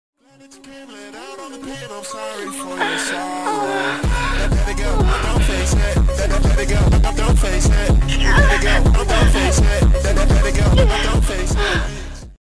educational female sounds!! with female